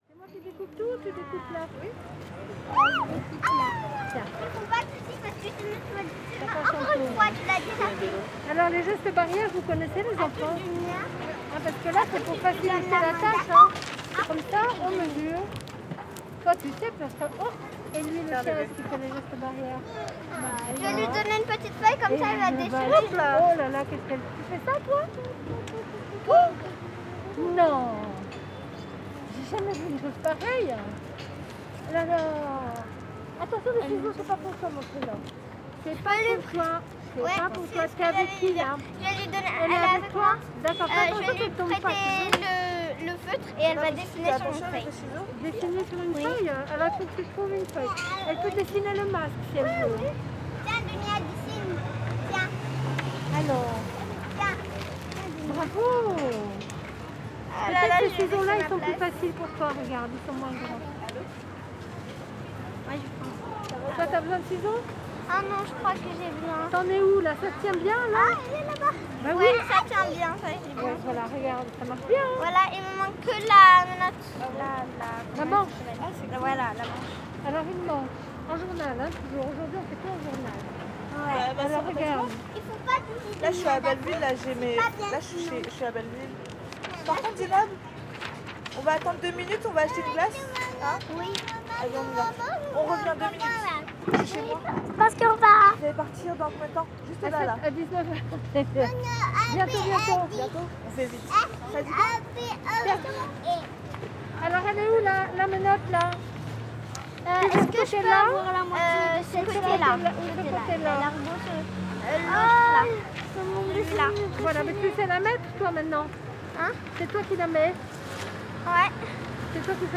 Atelier d'été pour les enfants avec des jeux en plein air, Boulevard de Belleville, 75020 Paris.
Prise de son à l'extérieur : À l'emplacement du marché, sur la table où les enfants faisaient des découpages.
fr CAPTATION SONORE
fr Boulevard de Belleville, Terre-Plein central
fr Scène de rue